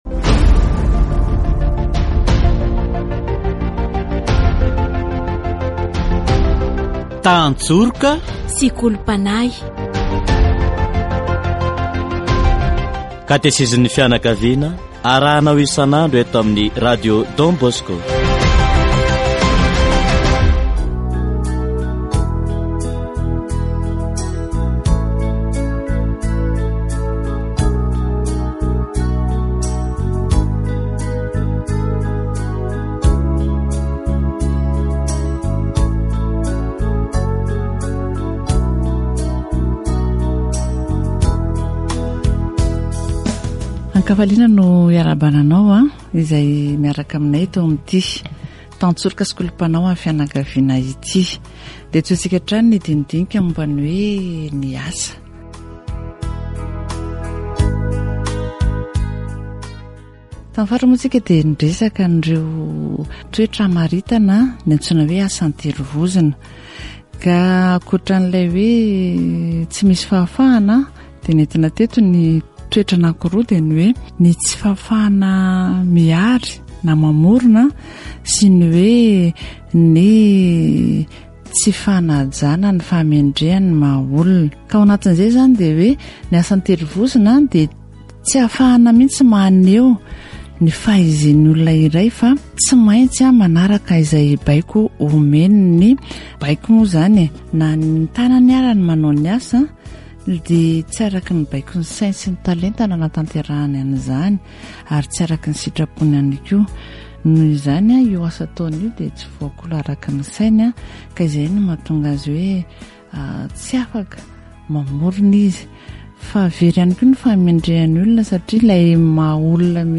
Katesizy momba ny asa